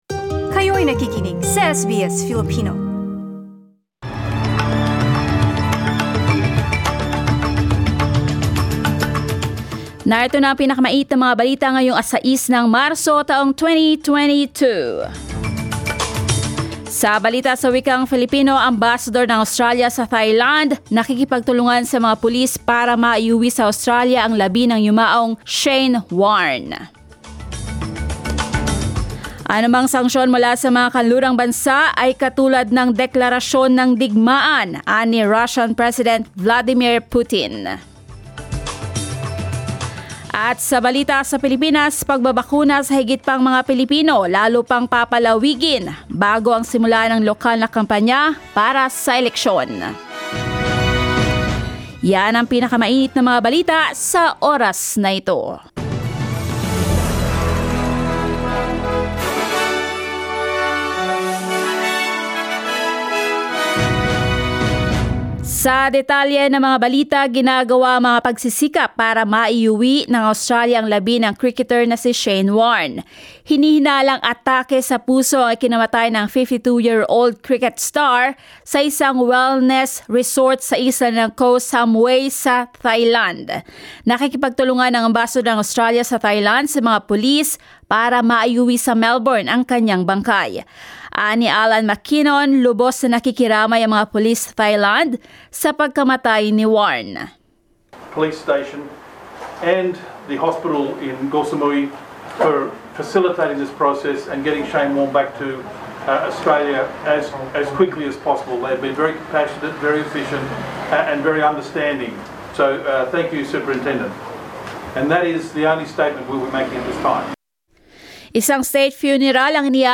SBS News in Filipino, Sunday 6 March